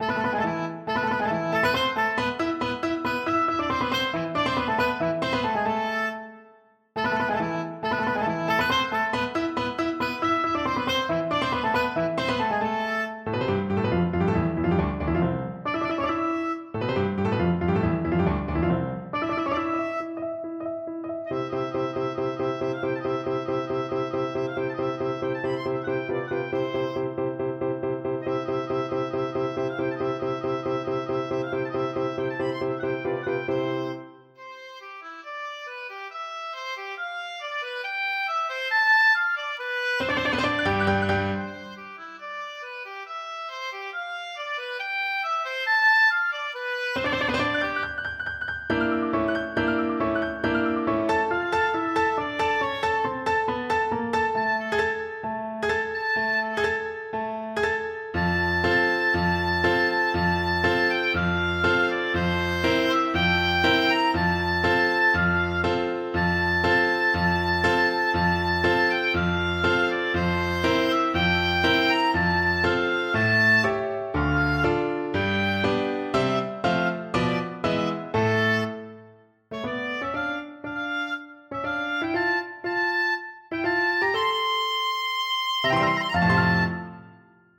Oboe
4/4 (View more 4/4 Music)
Allegro ( = 138) (View more music marked Allegro)
C major (Sounding Pitch) (View more C major Music for Oboe )
Classical (View more Classical Oboe Music)
anvil_chorusOB.mp3